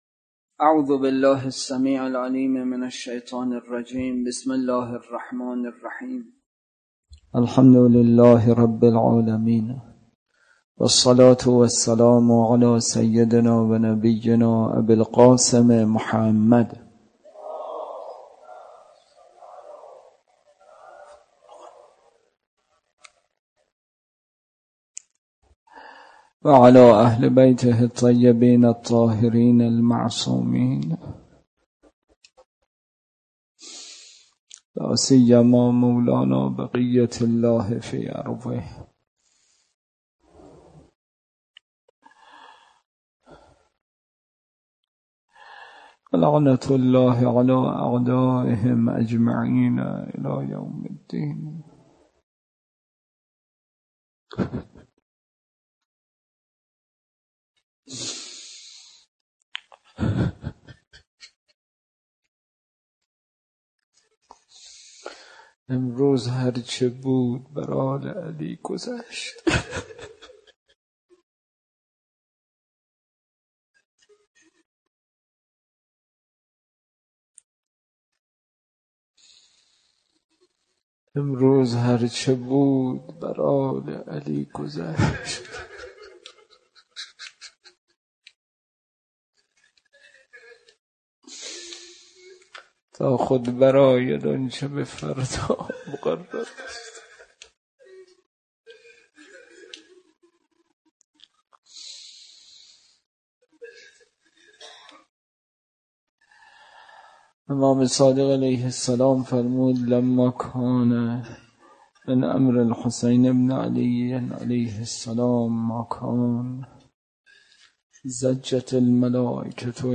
احکام: وظیفه‌ی جاهل قاصر در نماز بحث اصلی: راه‌های افزایش محبت به امام زمان(علیه‌السلام) روضه: شام غریبان
برگزارکننده: هیئت مصباح الهدی